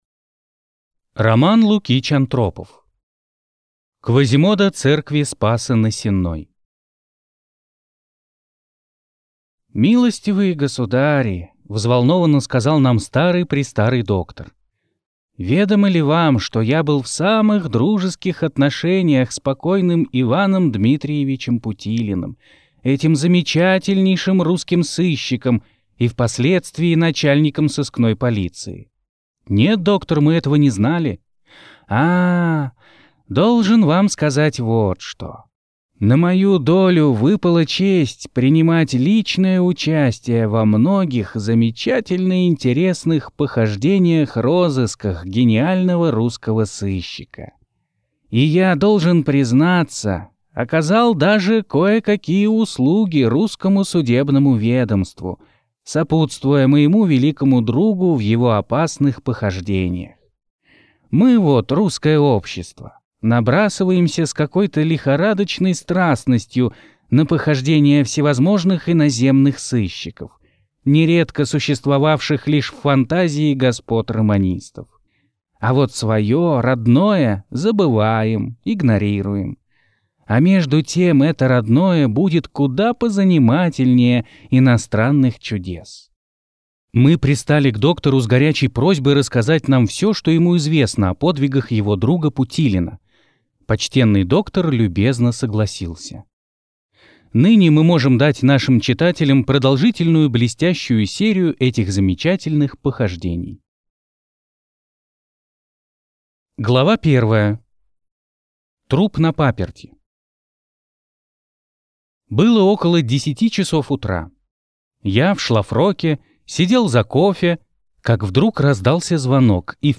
Аудиокнига Квазимодо церкви Спаса на Сенной | Библиотека аудиокниг